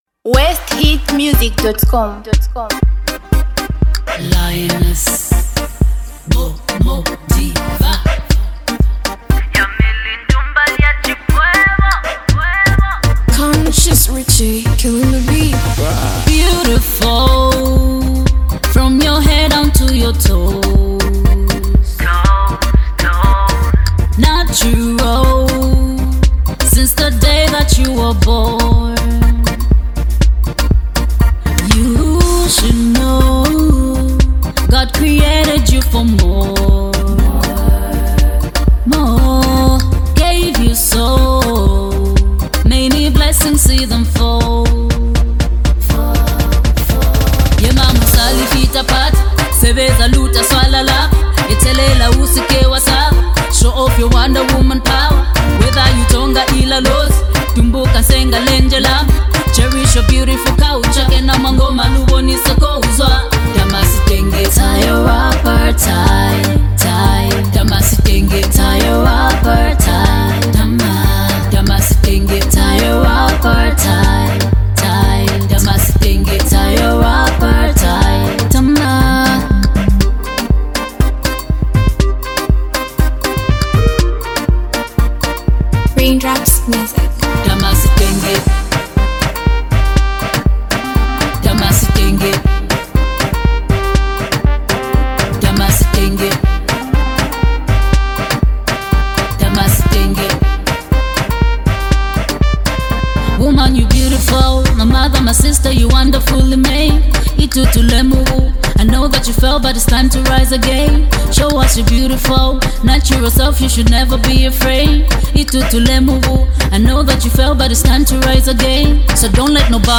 Category: Mbunga Music